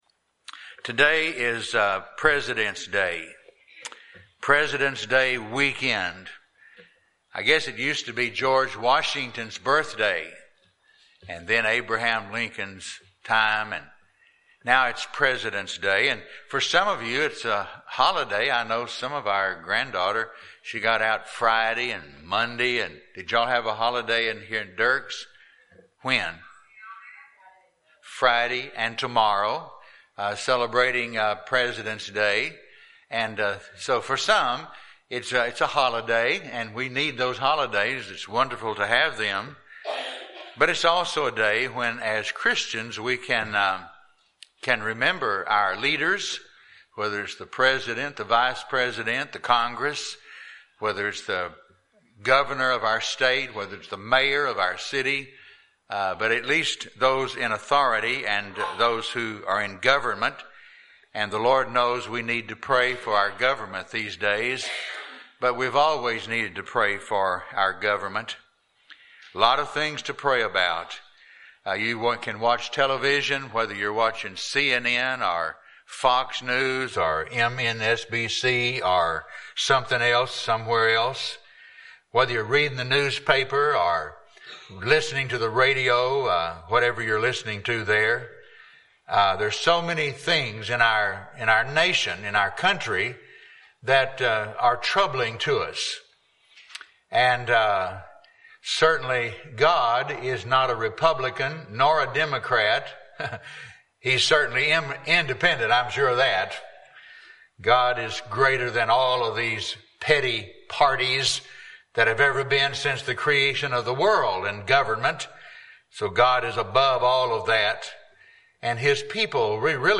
Passage: Romans 13:1-7 Service Type: Sunday Morning